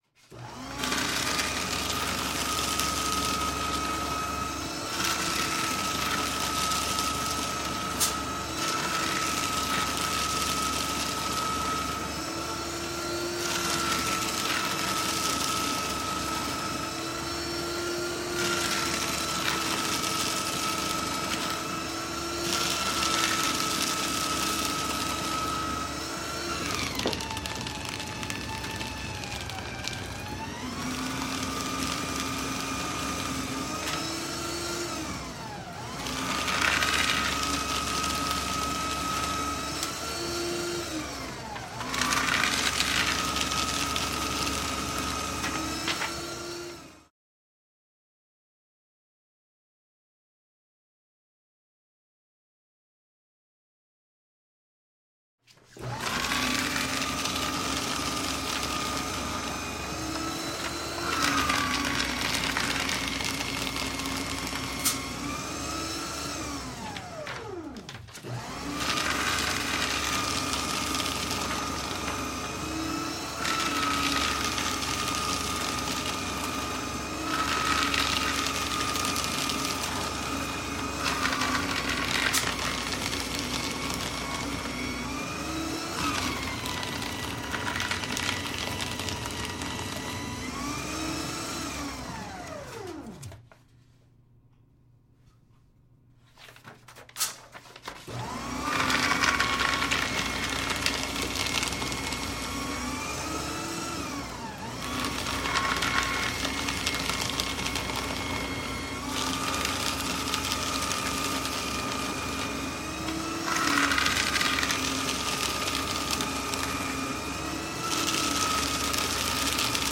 随机 " 碎纸机启动运行停止 咀嚼纸张各种附近的房间的办公室
描述：纸碎纸机开始运行停止munch纸各种附近宽敞的办公室
Tag: 碎纸机 运行 启动 停止